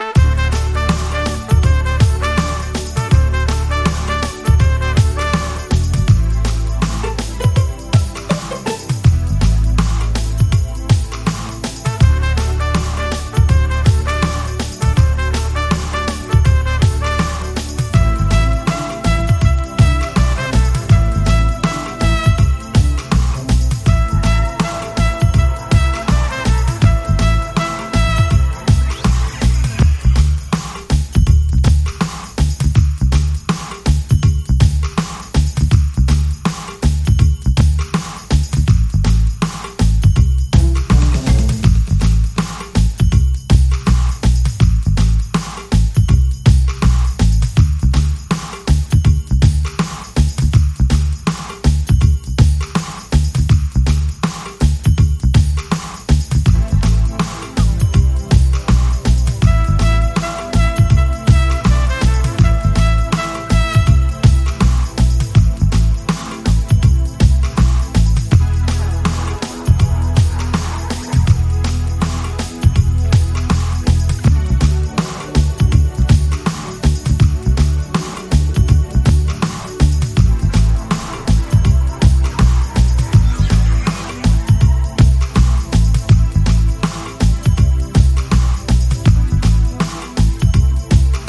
レゲエやダンスホール界のみならず、ヒップホップの現場でもプライされまくったビッグ・チューン。